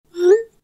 Звуки исходящего и входящего смс в переписке айфон.
1. Звук отправленного (исходящего) сообщения iPhone
iphone-send-w.mp3